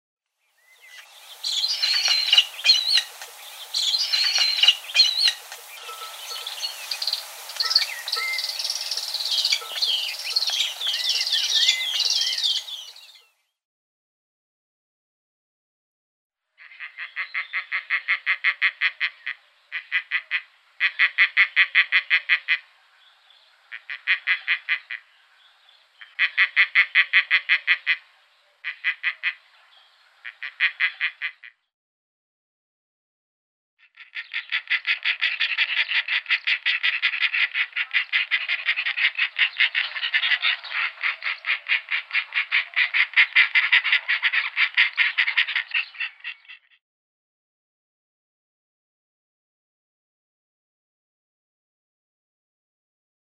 نام انگلیسی: Egyptian Vulture
نام علمی: Neophron percnopterus
آواز:
06a.Egyptian Vulture.mp3